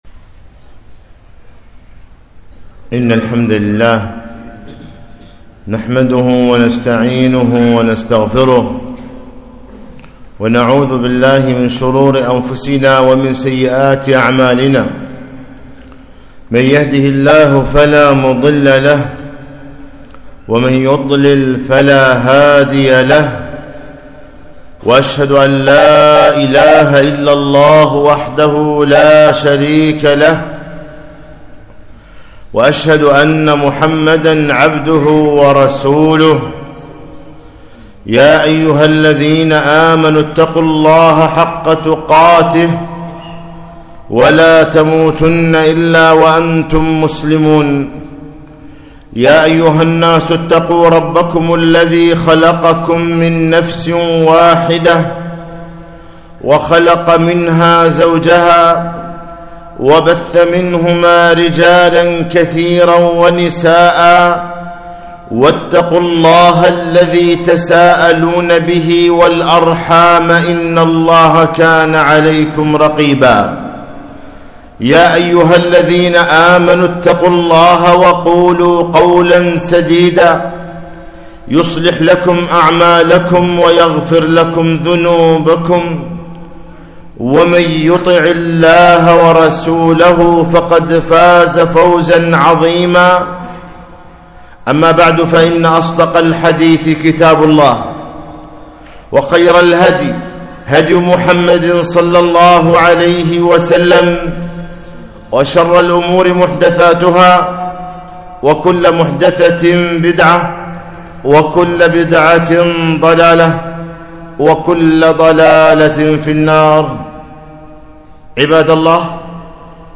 خطبة - (بشر الصابرين)